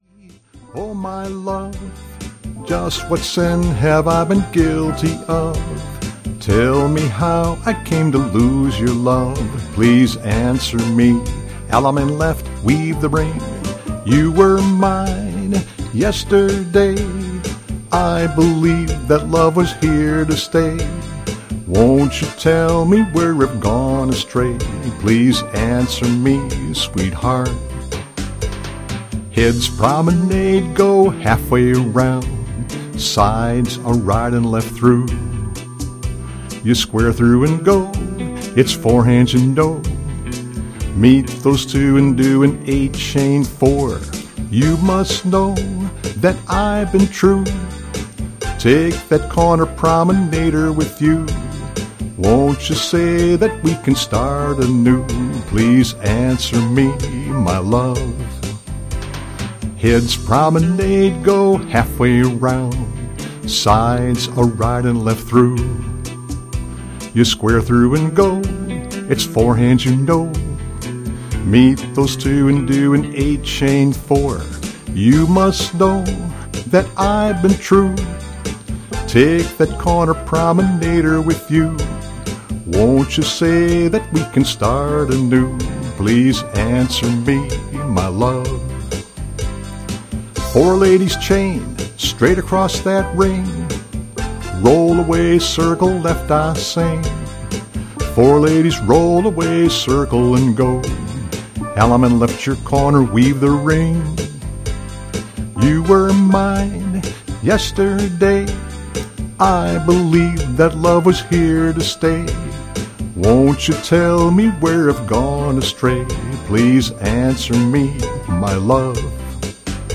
Singing Calls